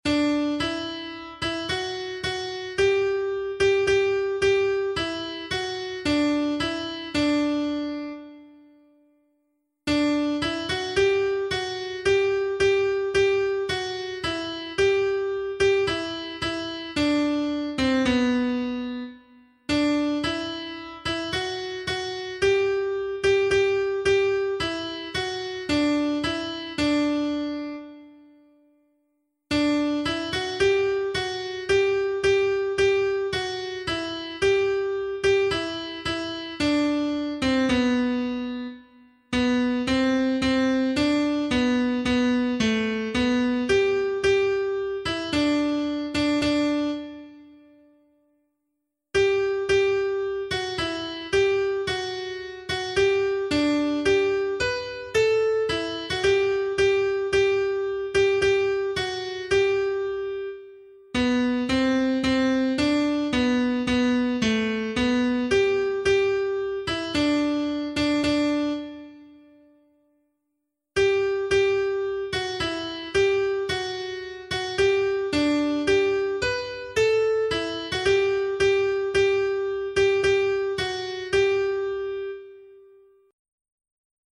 MIDI Sopranos